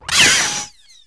hit1.wav